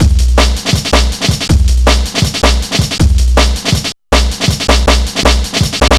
Index of /90_sSampleCDs/Zero-G - Total Drum Bass/Drumloops - 1/track 08 (160bpm)